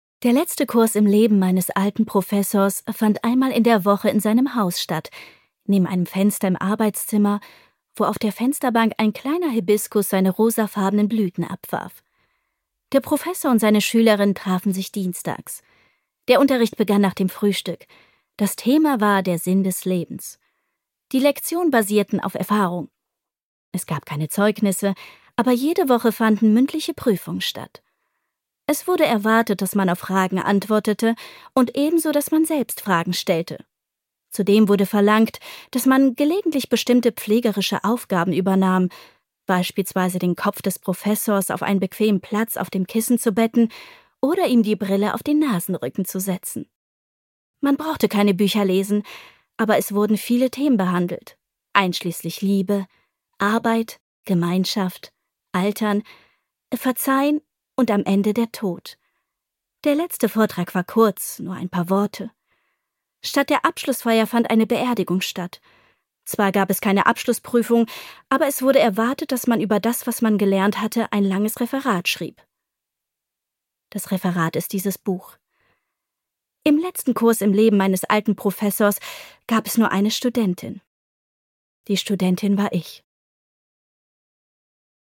Hoerbuch.mp3